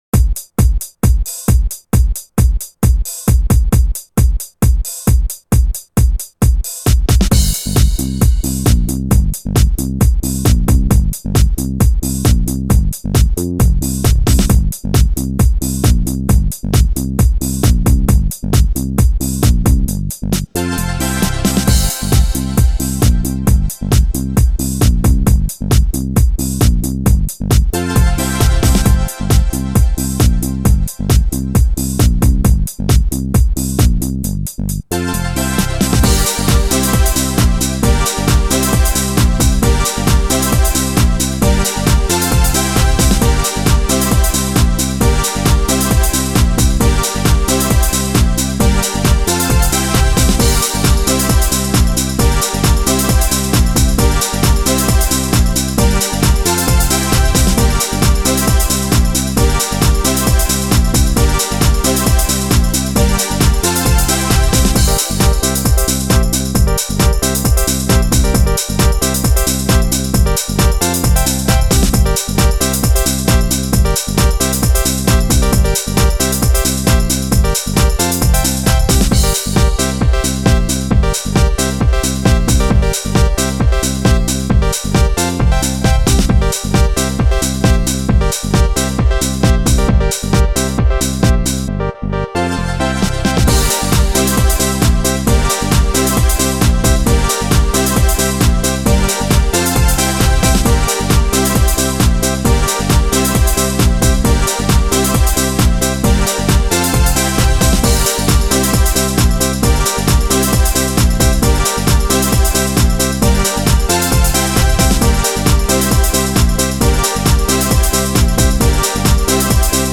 finest electronic music.